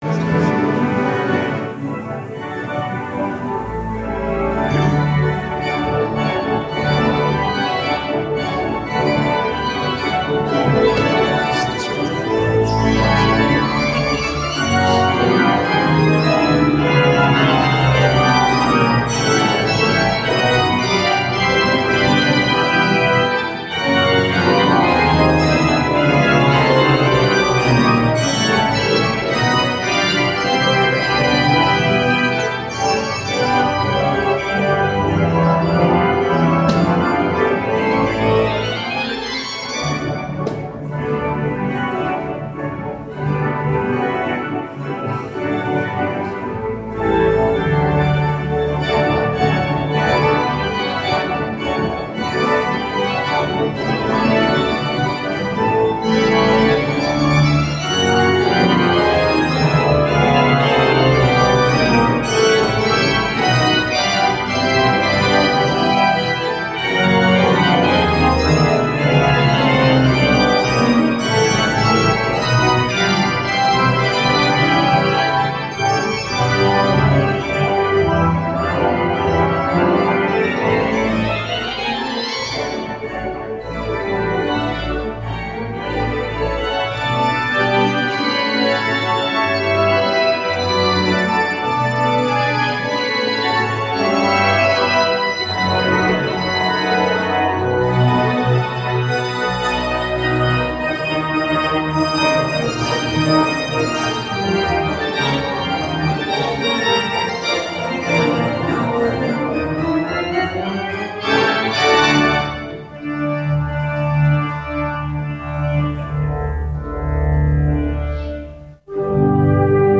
Kreuzkirche Dresden Silvester-Orgelkonzert Mi 31.12.2014 21:00 Uhr Festliche Musik für 7 Trompeten, Pauken und Orgel
Dresdner Trompeten Consort der Sächsischen Staatskapelle
Orgel